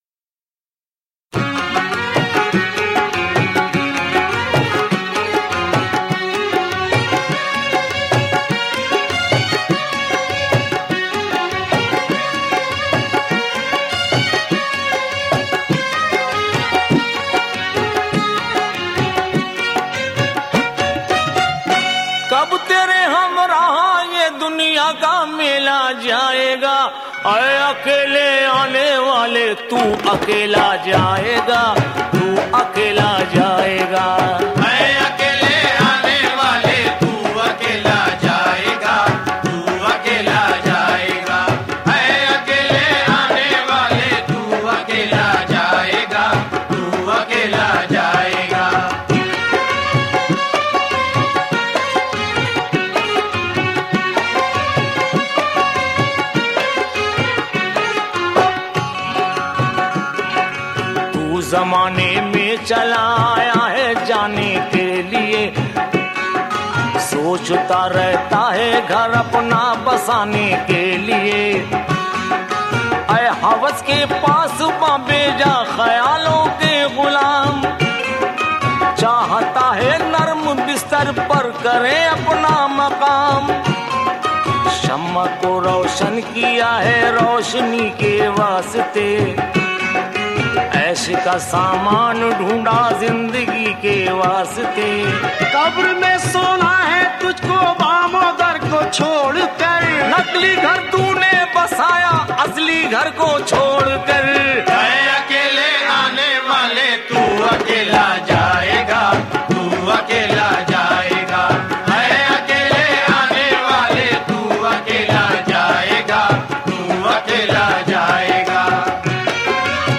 Urdu Qawwali MP3